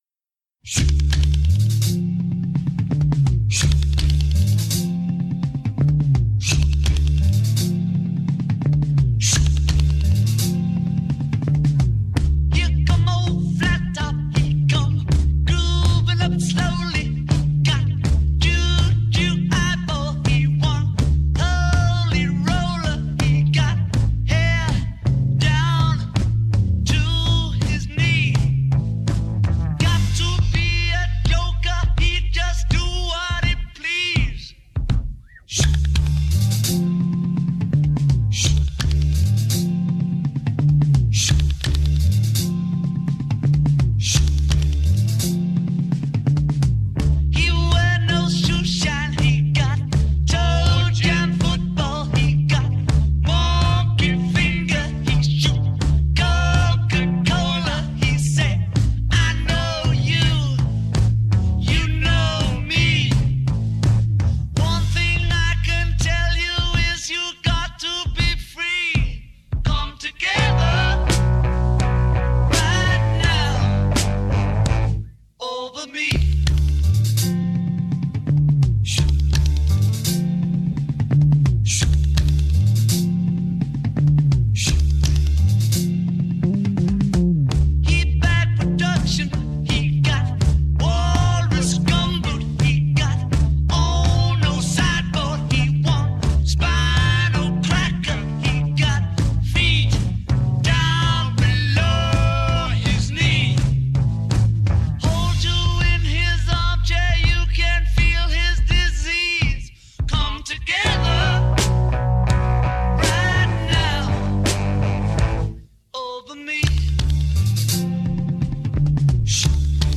Un’intervista monografica nella trasmissione Pijipedia, l’enciclopedia minima